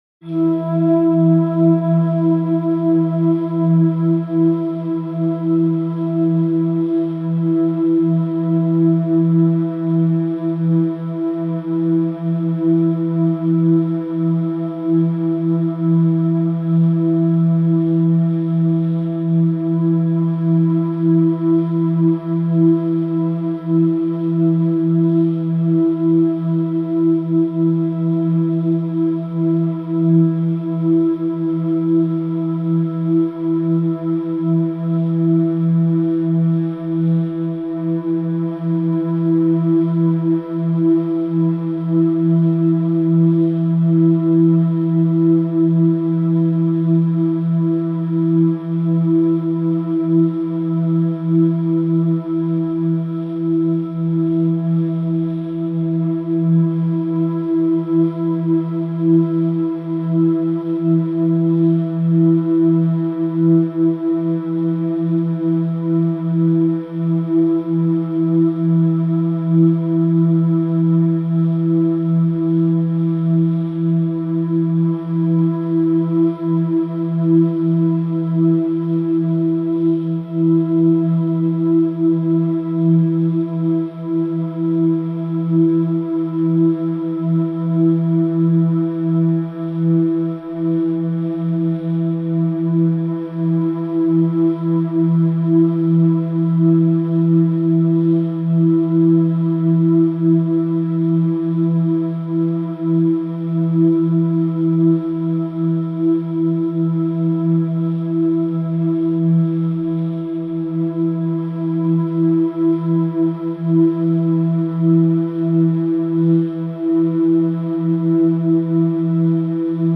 Royalty free music elements: Tones
mf_SE-8677-soft_airy_tone.mp3